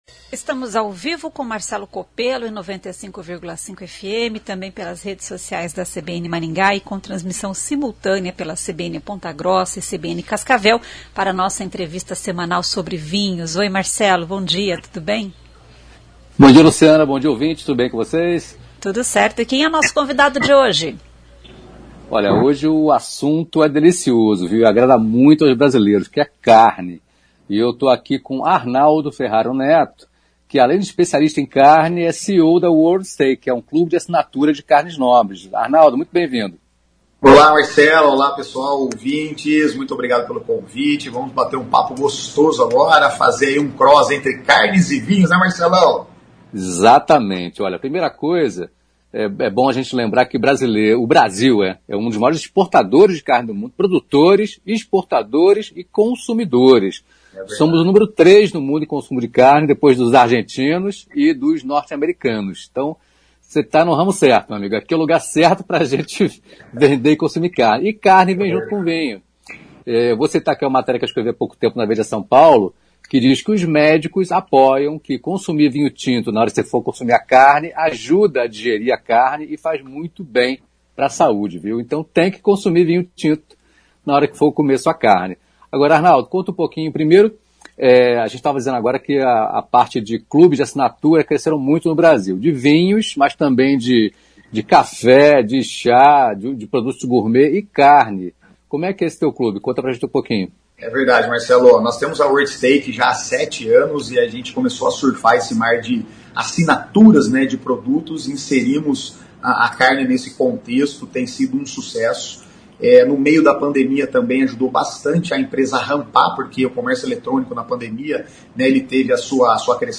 conversa com especialista em carnes